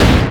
IMPACT_Generic_05_mono.wav